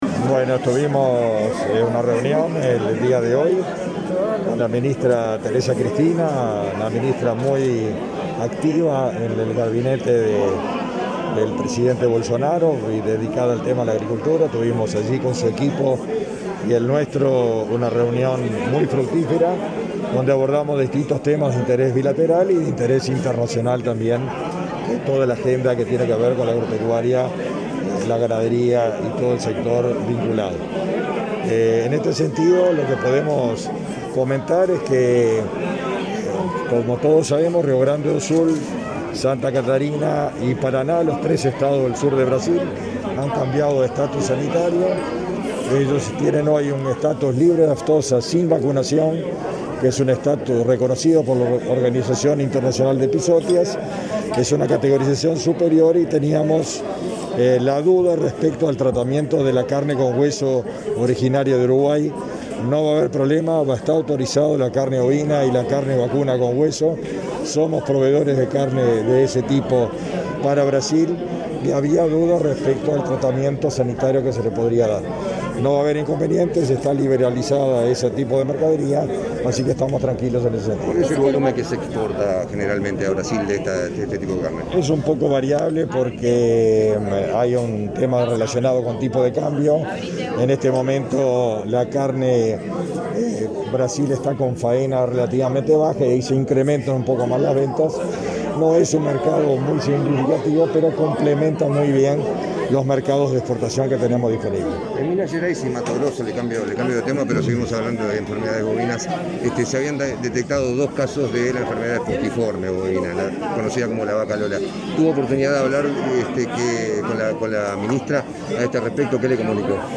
Declaraciones del ministro de Ganadería, Agricultura y Pesca, Fernando Mattos, a medios informativos
Declaraciones del ministro de Ganadería, Agricultura y Pesca, Fernando Mattos, a medios informativos 10/09/2021 Compartir Facebook X Copiar enlace WhatsApp LinkedIn Al finalizar el acto de inauguración del stand del Ministerio en la Rural del Prado, este 10 de setiembre, Fernando Mattos efectuó declaraciones a la prensa.